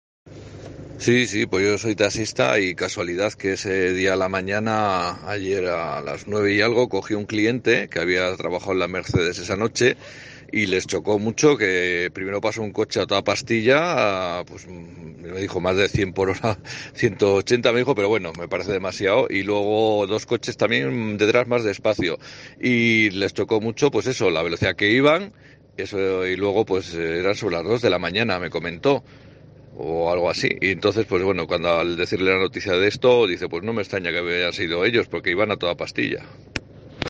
Audio testimonio sonido coche gran velocidad